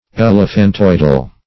Search Result for " elephantoidal" : The Collaborative International Dictionary of English v.0.48: Elephantoid \El"e*phan*toid`\ (?; 277), Elephantoidal \El`e*phan*toid"al\, a. [Elephant + -oid.]